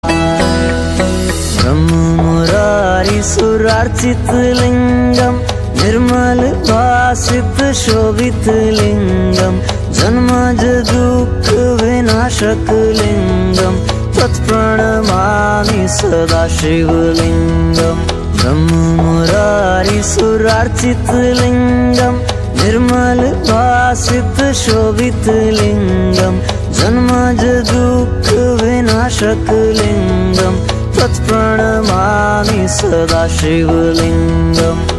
best flute ringtone download | shiva song ringtone
bhajan ringtone